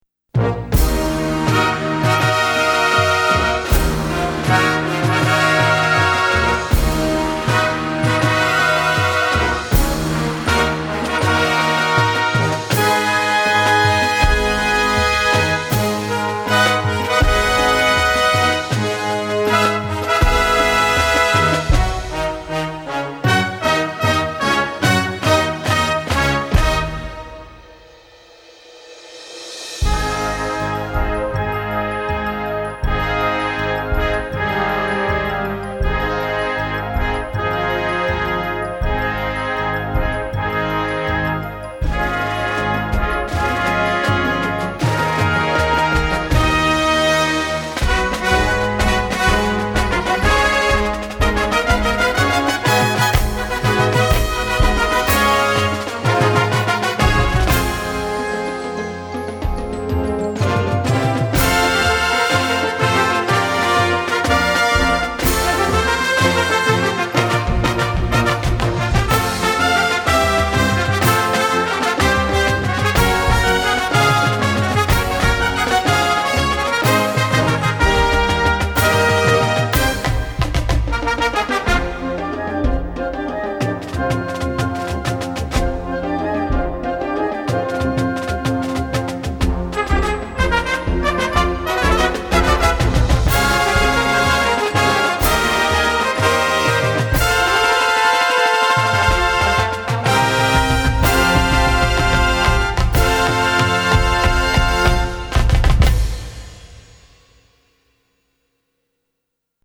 Gattung: Filmmusik
B-C Besetzung: Blasorchester Tonprobe